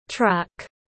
Truck /trʌk/